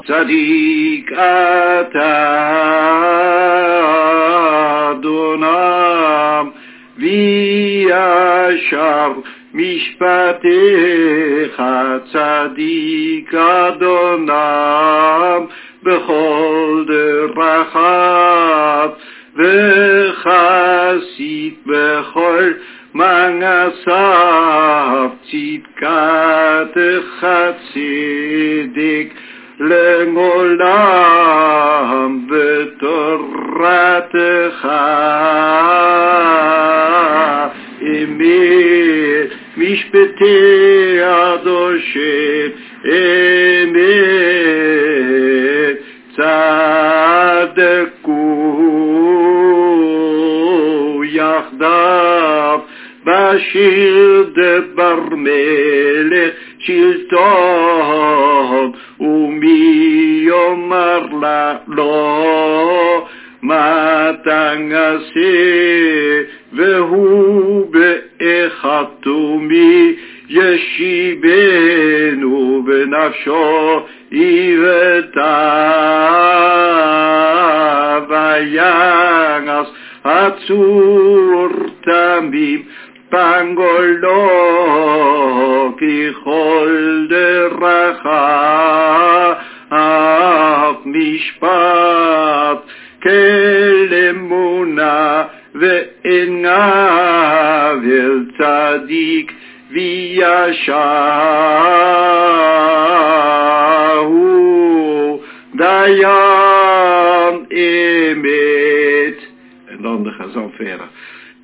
sung.